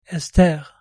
"ess-TER"